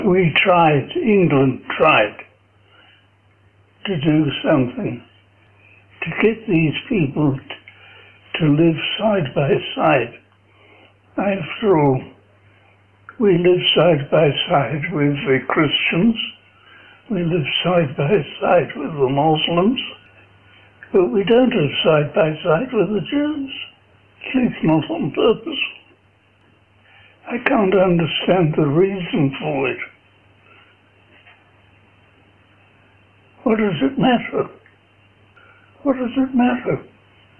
mluvil pomalu a některé otázky si musel nechat opakovat